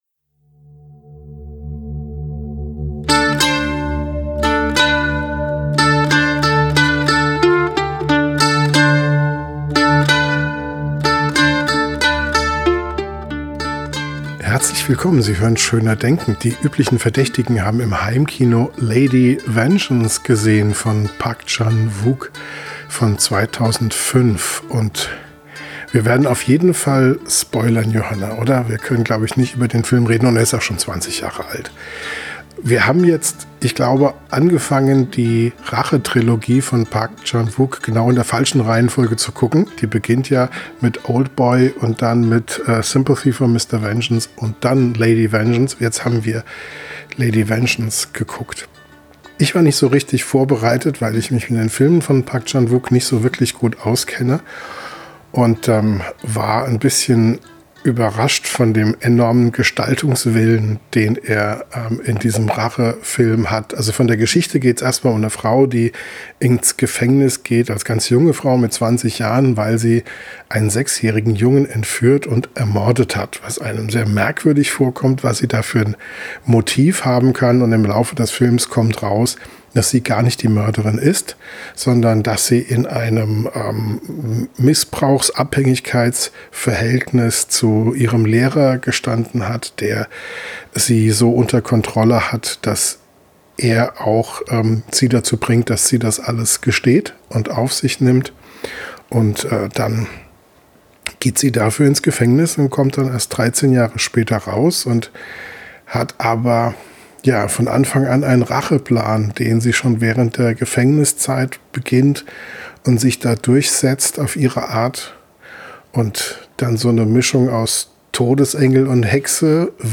Im Podcast direkt nach dem Film diskutieren wir unter anderem darüber, was das alles mit Vivaldi und Tofu-Ritualen zu tun hat.